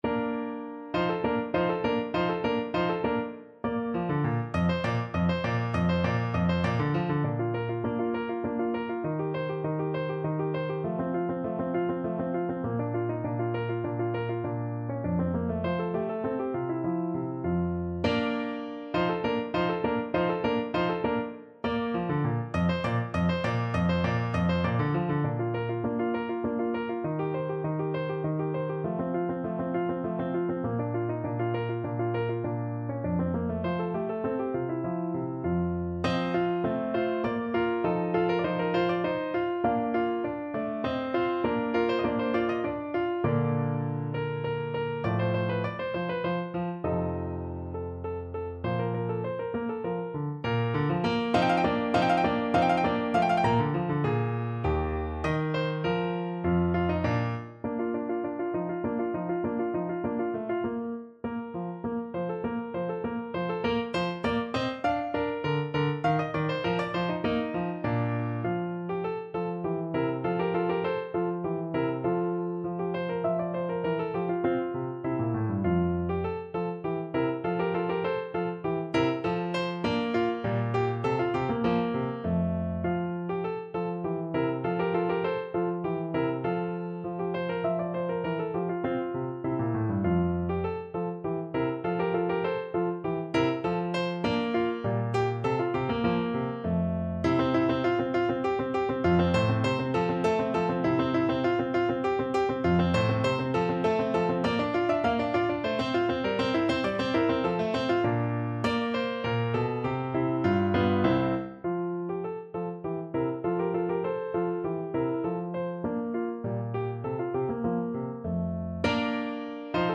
3/4 (View more 3/4 Music)
Moderato
Classical (View more Classical Flute Music)